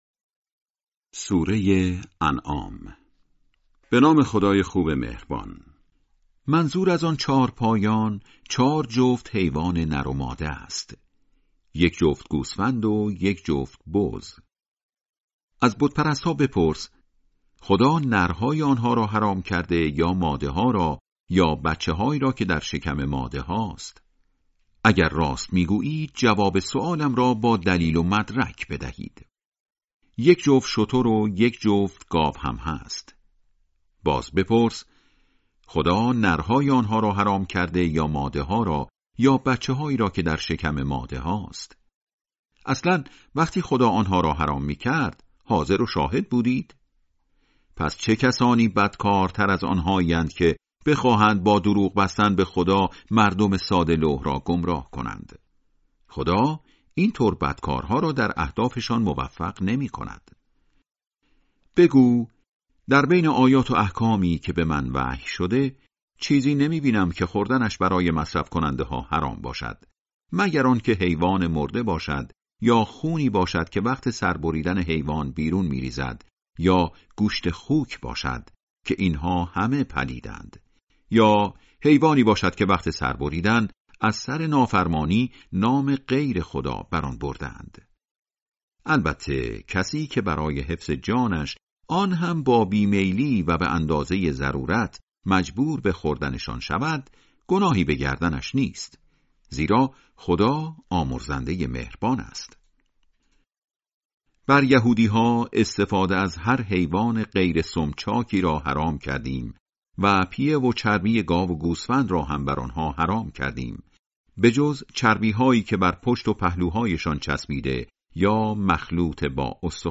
ترجمه سوره(انعام)